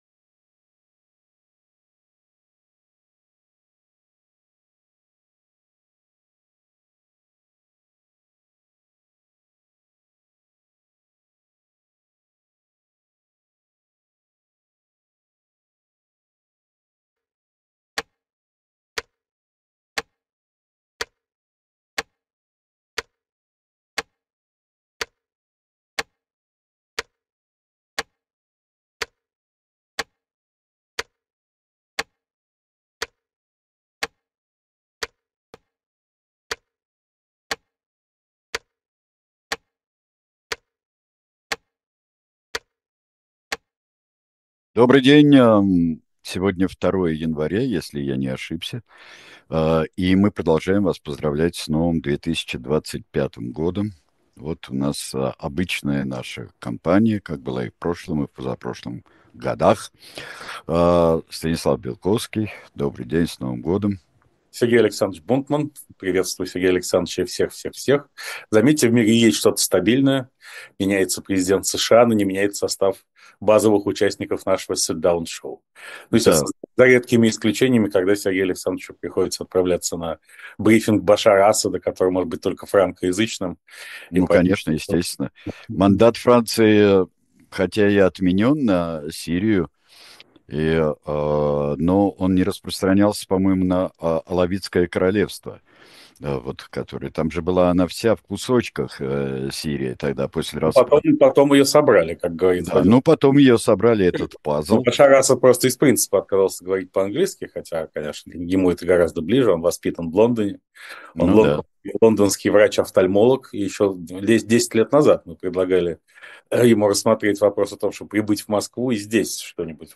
Эфир ведёт Сергей Бунтман